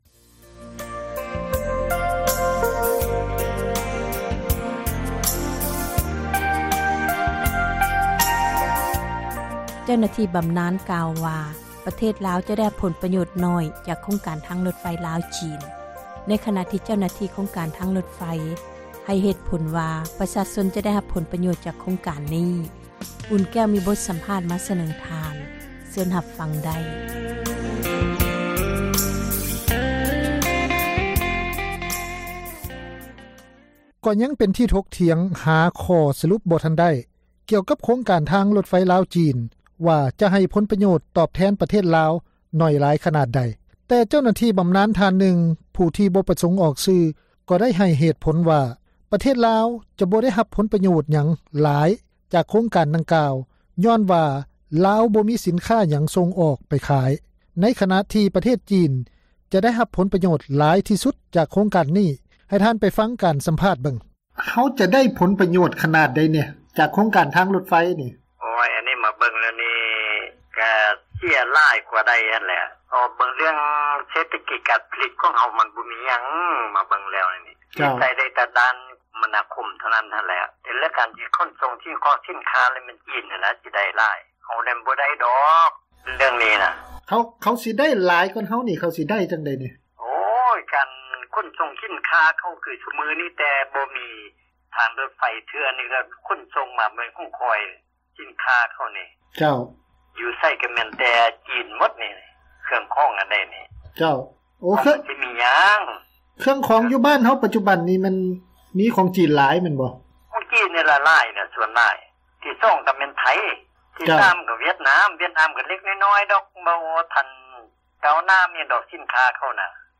(ສຽງສັມພາດ)